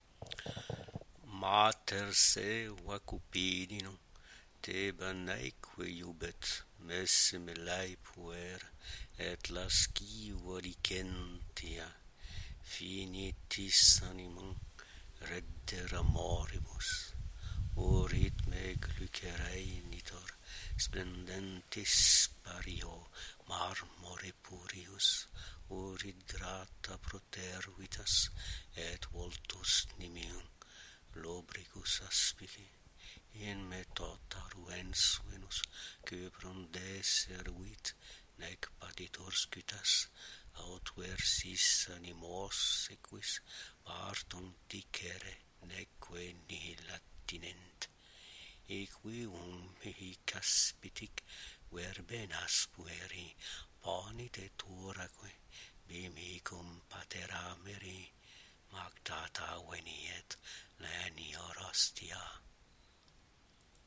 Texte scandé en latin :
Le mètre est d’un glyconique suivi d’un asclépiade mineur :